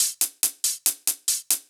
UHH_ElectroHatA_140-04.wav